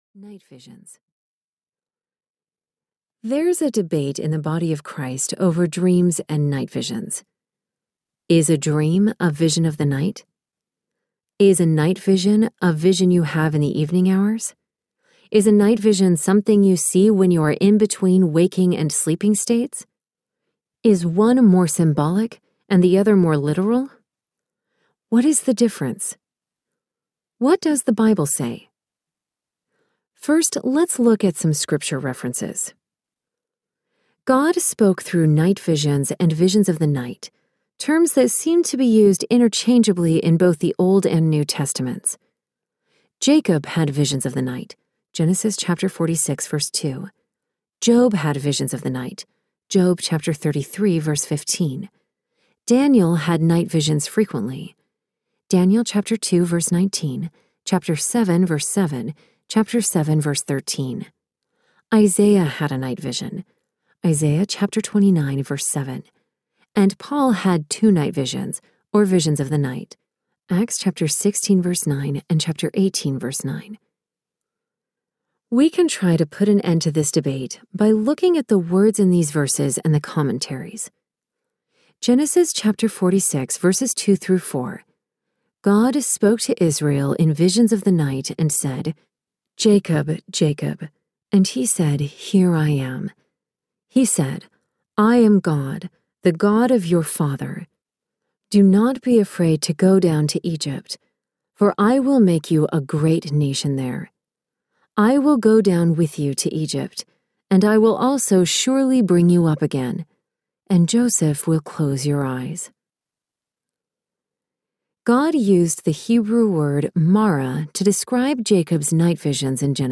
Decoding Your Dreams Audiobook
Narrator
6.25 Hrs. – Unabridged